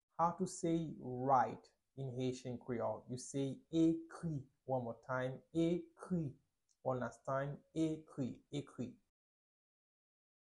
Listen to and watch “Ekri” audio pronunciation in Haitian Creole by a native Haitian  in the video below:
17.How-to-say-write-in-haitian-creole-–-Ekri-pronunciation-.mp3